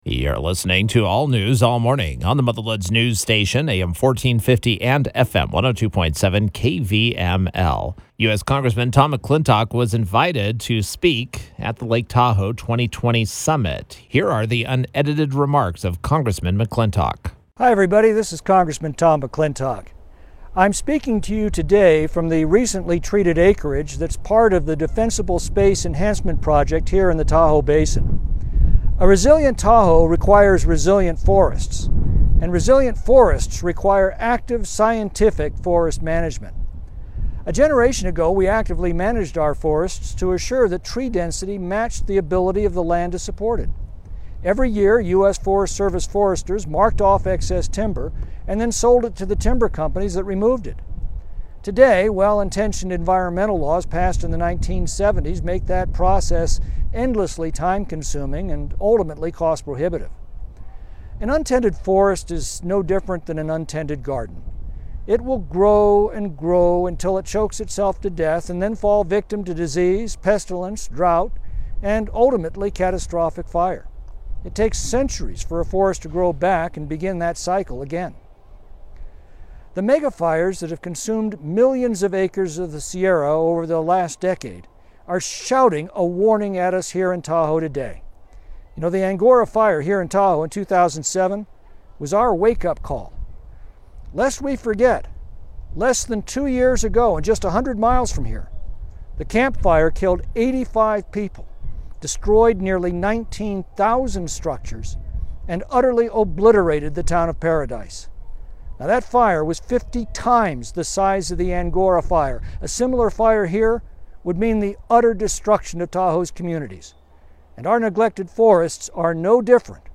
Congressman Tom McClintock (R-Calif.) spoke (virtually) at this year’s annual Lake Tahoe Summit.